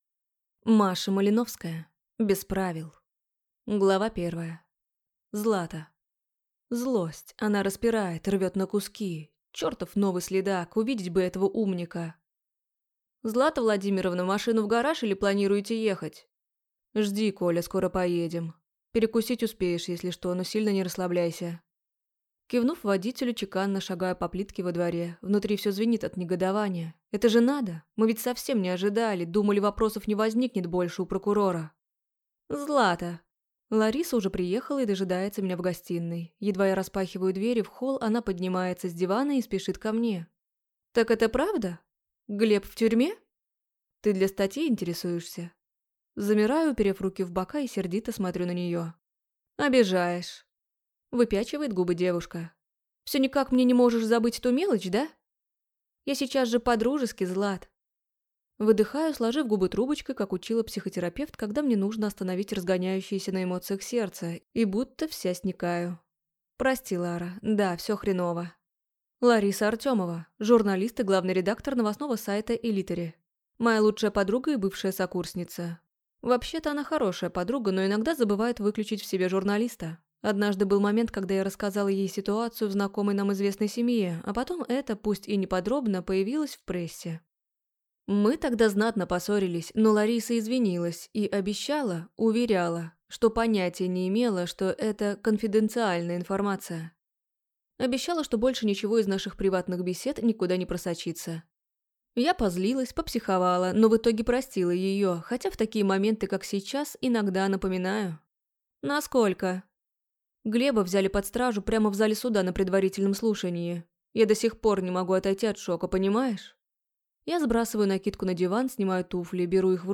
Aудиокнига Без правил